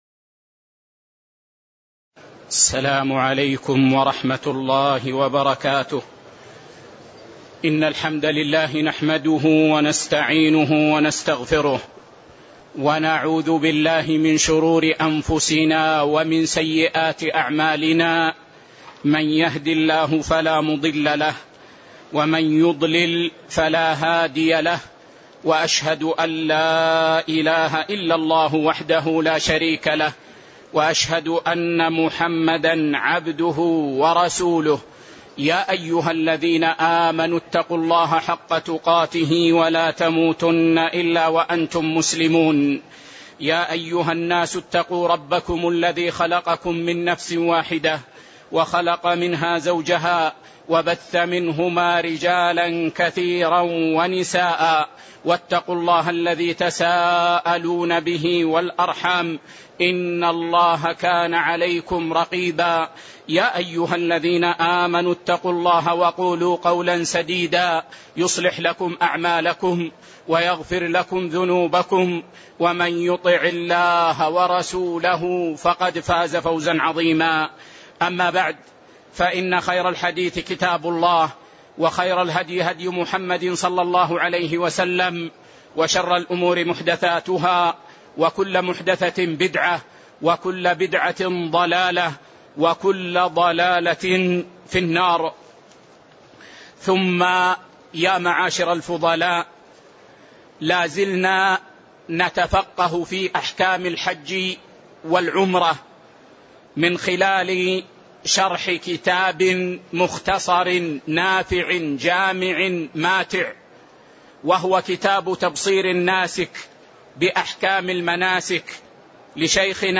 تاريخ النشر ٢١ ذو القعدة ١٤٣٨ هـ المكان: المسجد النبوي الشيخ